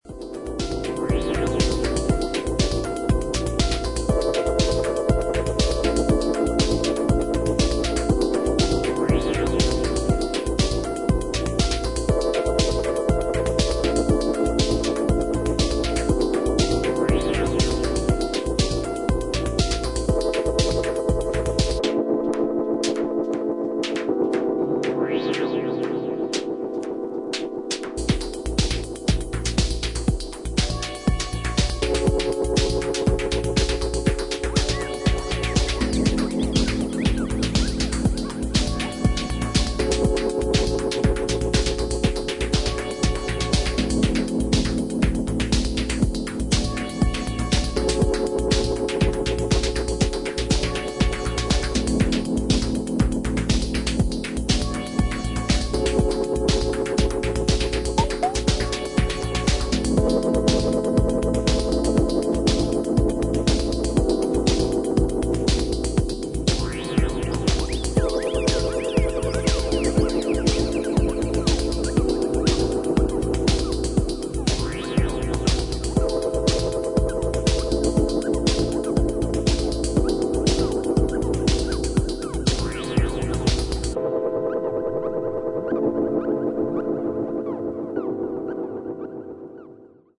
ハードウェアシンセサイザーの力を思う存分に振るったアンビエントテクノを披露